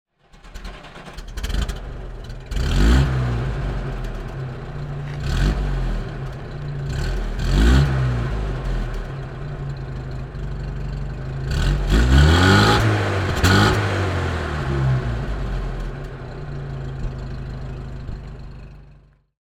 Renault Frégate Domaine (1957) - Starten und Leerlauf
Renault_Fregatte.mp3